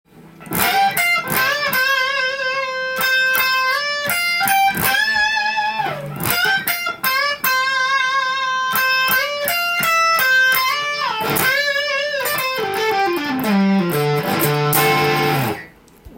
音の方は、ギブソンのような太い音がして
試しに弾いてみました
チョーキングすると男気溢れるロックサウンドが出てきます。